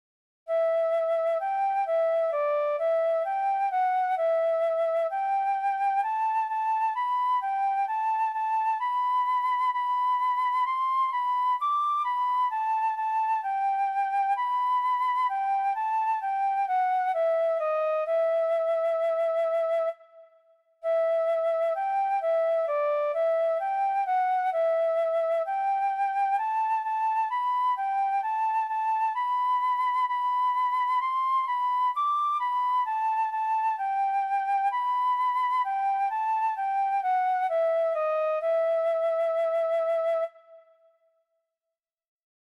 001 0 Jah keep us steadfast in thy Word - FLUTE.mp3